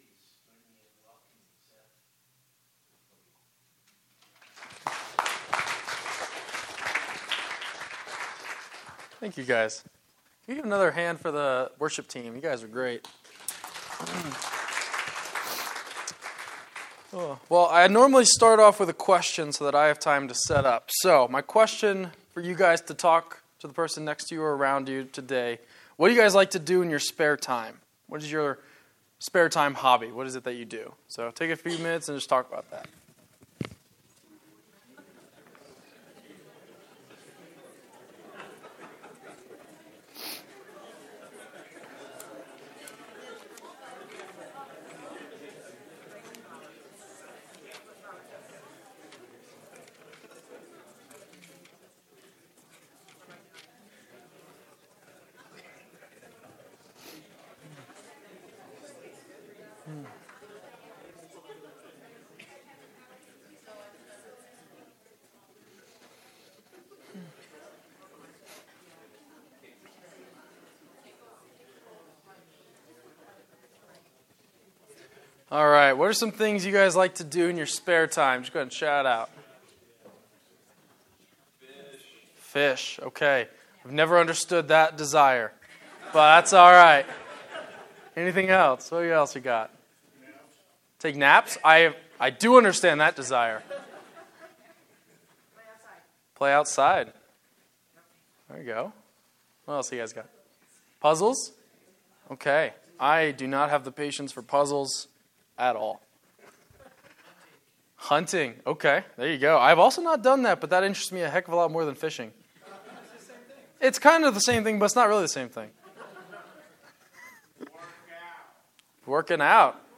Sermon-11-5-23.mp3